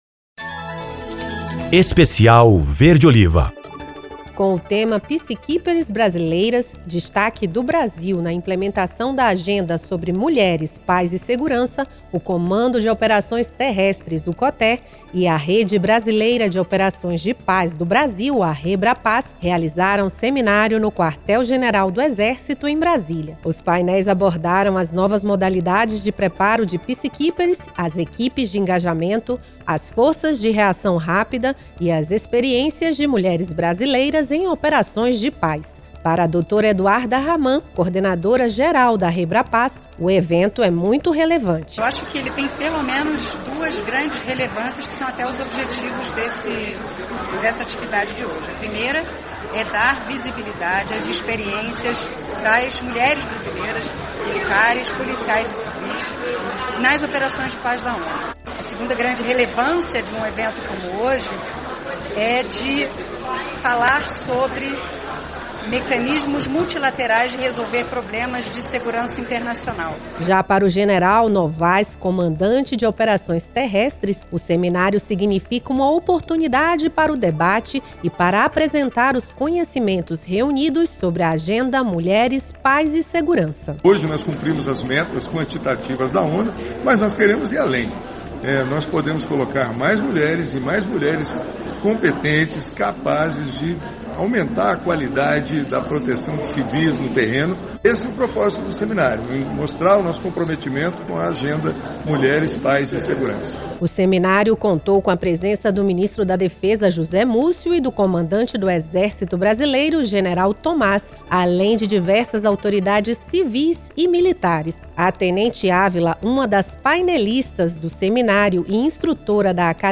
Exército Brasileiro. Matéria da Rádio Verde-Oliva (3min), veiculada em Brasília, Manaus, Resende, Três Corações e Santa Maria. 22/03/2024.